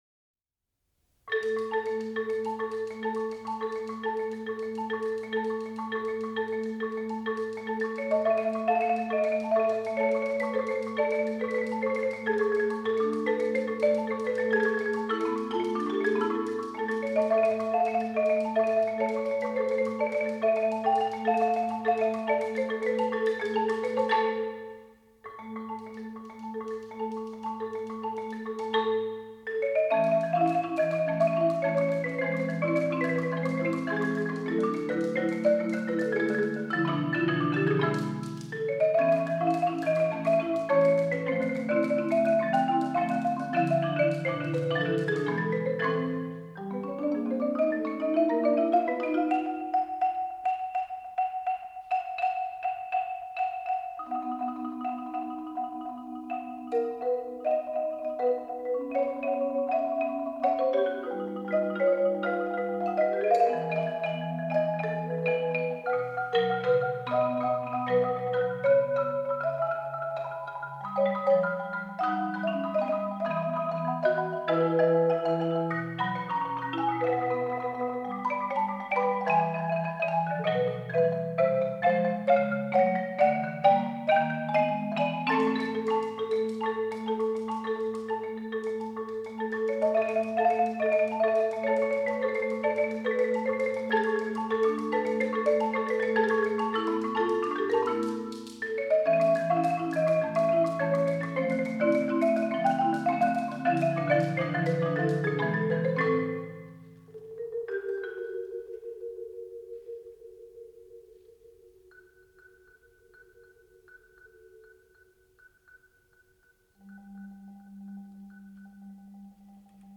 Genre: Marimba Quartet
# of Players: 4